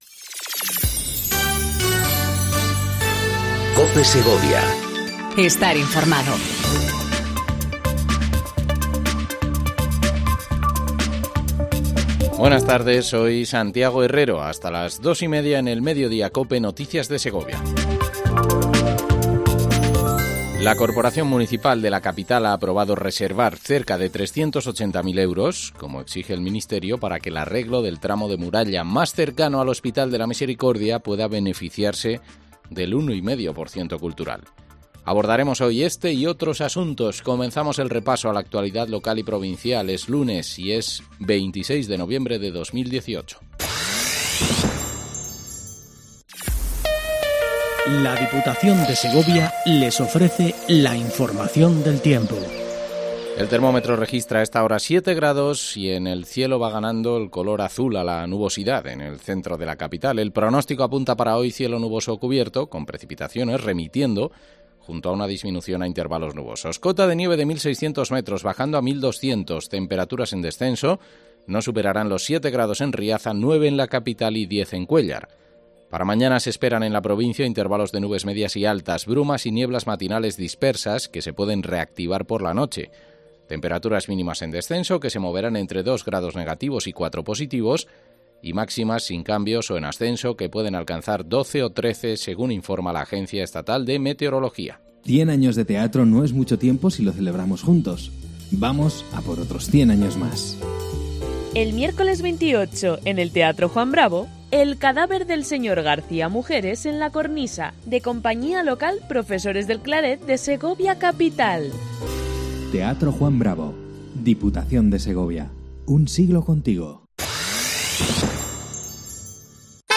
AUDIO: Repaso informativo a la actualidad local y provincial 26/11/18